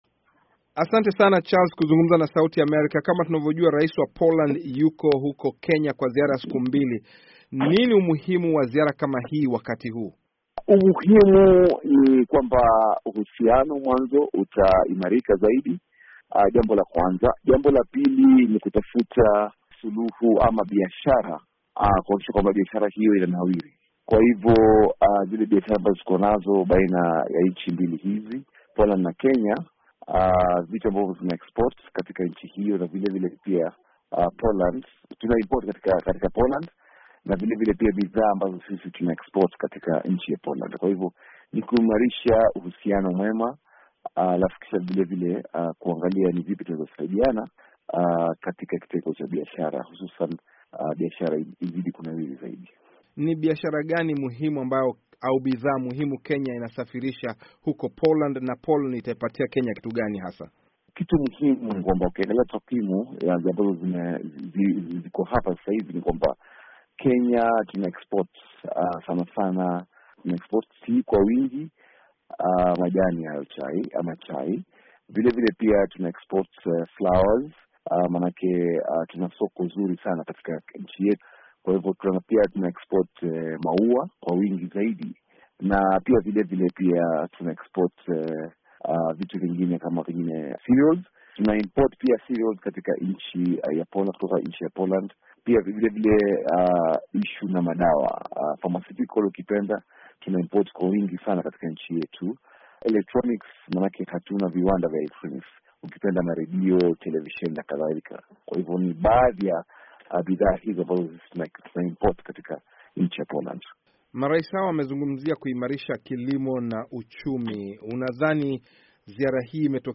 Rais wa Poland Duda akizungumza na waandishi habari Nairobi.